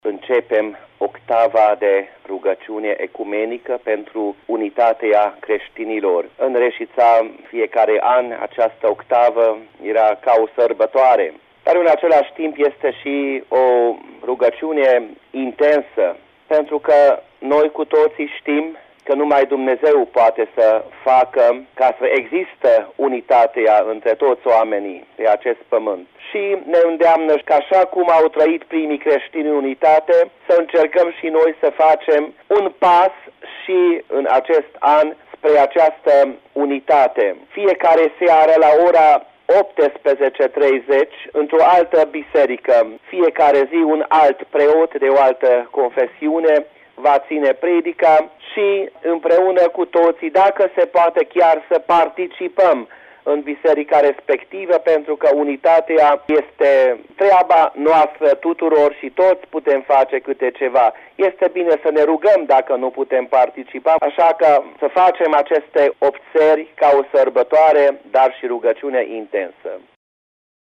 ecumenica1Credincioşii celor 7 culte din municipiul Reşiţa s-au întâlnit în seara de sâmbătă, 18 ianuarie la Biserica Evanghelică Lutherană din urbe pentru a participa la prima slujbă organizată în cadrul săptămânii ecumenice pentru unitatea creştinilor.